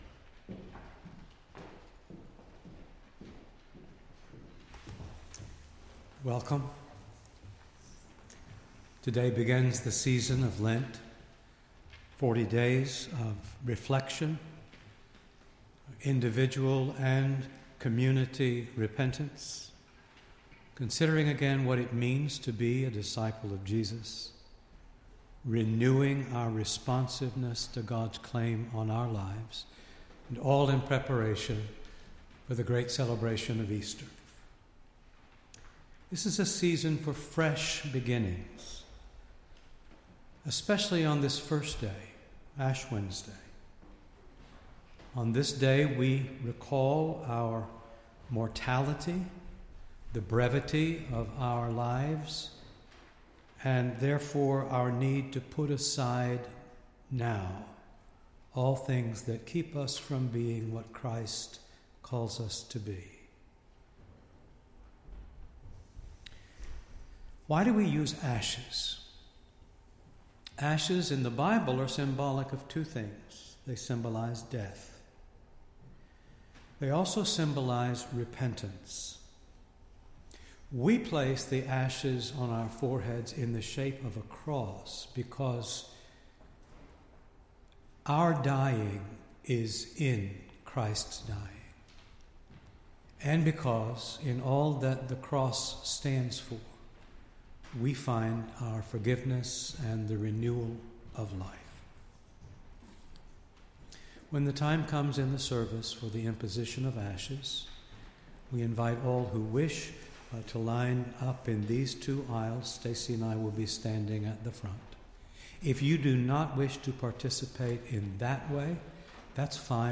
Entire March 1st Service